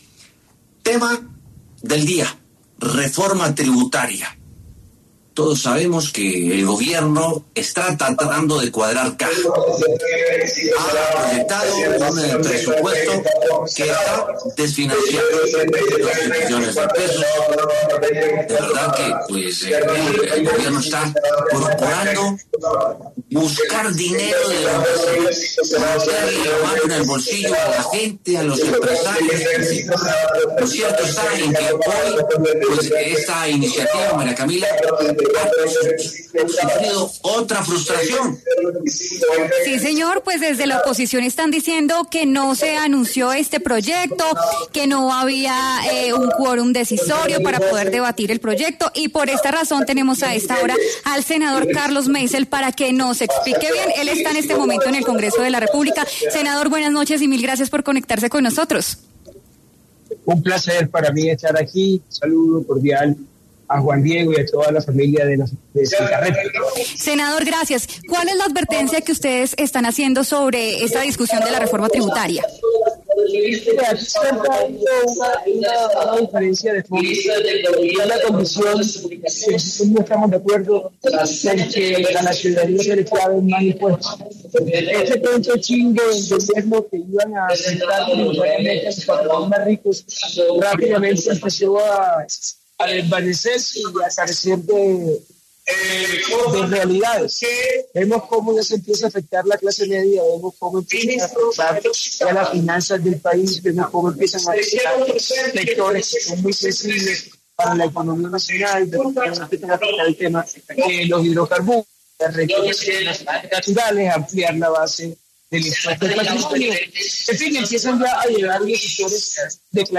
El senador Carlos Meisel y Carlos Carreño, congresista del Partido Comunes, debatieron sobre el anuncio de la discusión del proyecto de la reforma tributaria.
El senador Carlos Meisel y Carlos Carreño, congresista del Partido Comunes, pasaron por los micrófonos de W Sin Carreta para hablar sobre la discusión del proyecto de ley de financiamiento o reforma tributaria del Gobierno del presidente Gustavo Petro que anunciaron las comisiones económicas del Congreso.